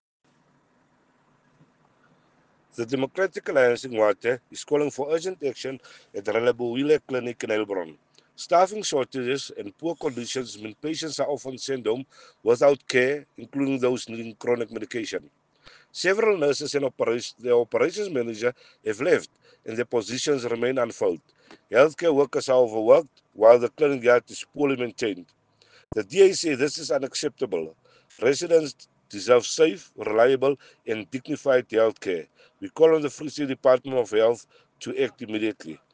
Afrikaans soundbites by Cllr Robert Ferendale and